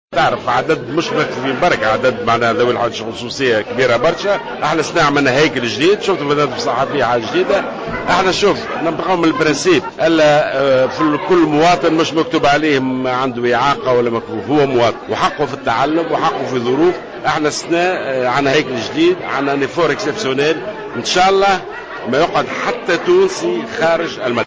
أكد وزير التربية ناجي جلول خلال اشرافه اليوم الثلاثاء 15 سبتمبر 2015 على انطلاق العودة المدرسية بمدرسة المكفوفين ببئر القصعة أن عدد ذوي الحاجيات الخصوصية في تونس كبير جدا مشيرا الى أحقيتهم بالتعليم مثلهم مثل كل المواطنين.